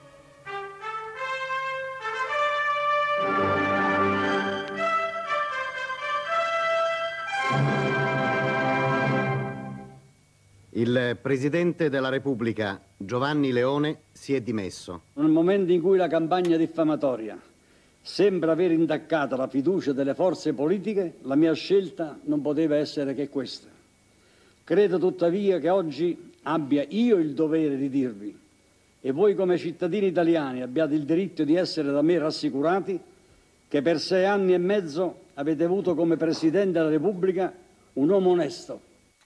Giugno 1978: mancano sei mesi alla fine del mandato presidenziale e il presidente della repubblica, Giovanni Leone, si rivolge alla nazione e pronuncia questo discorso, ripreso dal telegiornale: